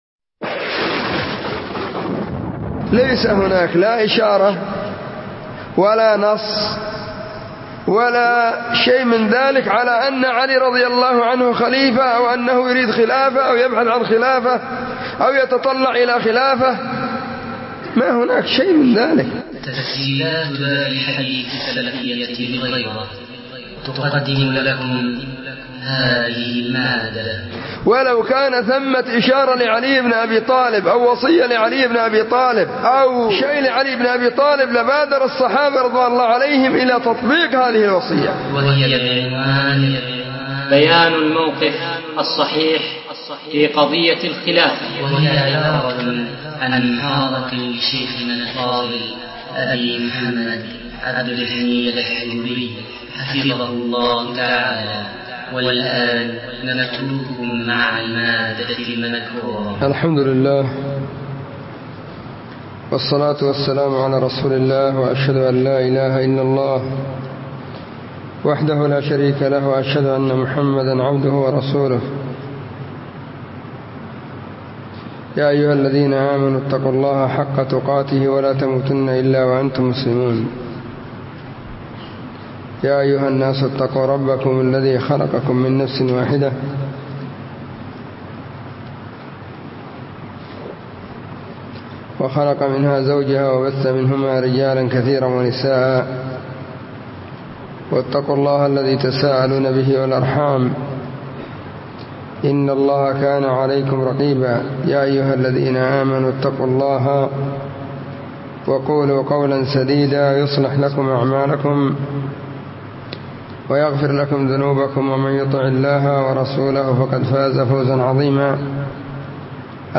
محاضرة بعنوان *بيان الموقف الصحيح في قضية الخلافة*
📢 مسجد الصحابة – بالغيضة – المهرة، اليمن حرسها الله،